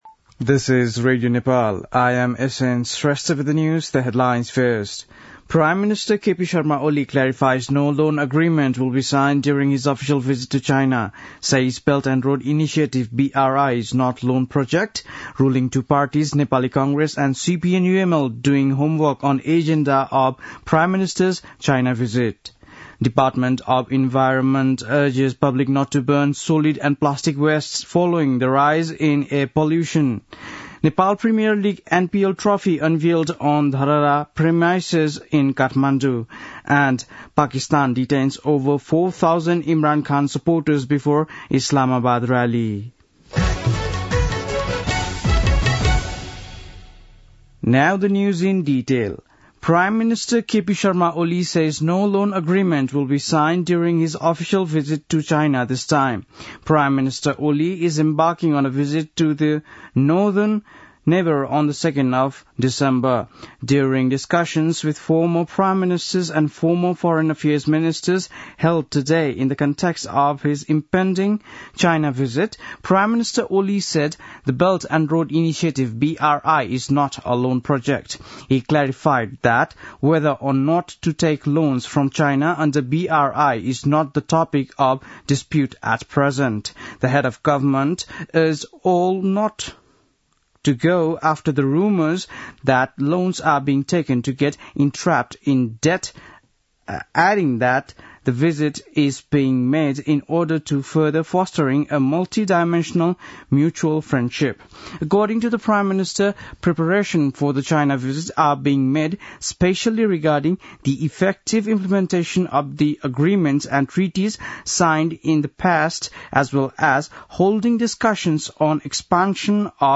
बेलुकी ८ बजेको अङ्ग्रेजी समाचार : ११ मंसिर , २०८१